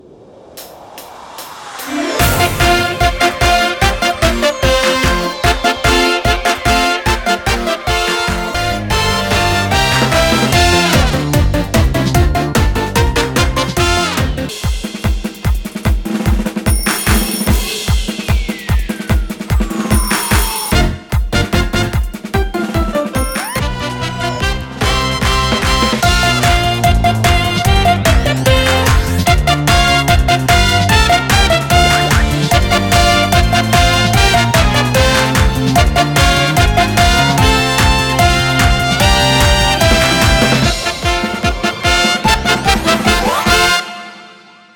難易度 初〜中 分類 駈足148 時間 2分40秒
編成内容 大太鼓、中太鼓、小太鼓、シンバル、トリオ 作成No 374